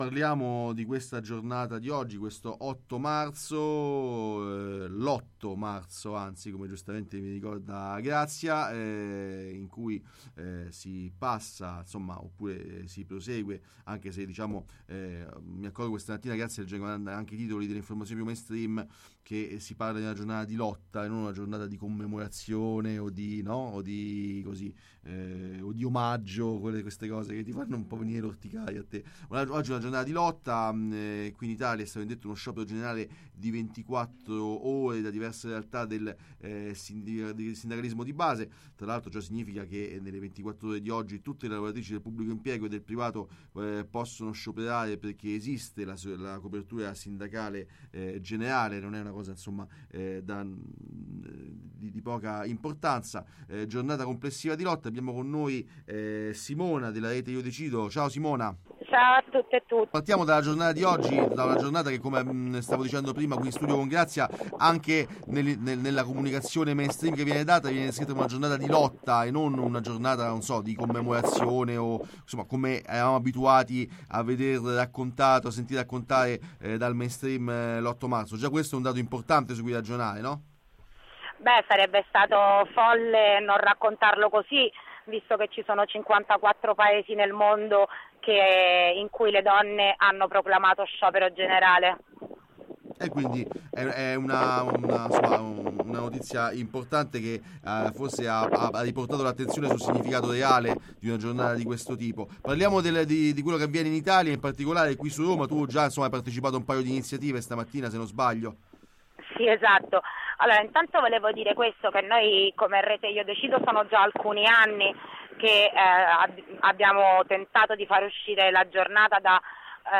Lotto Marzo: intervista Rete Io Decido | Radio Città Aperta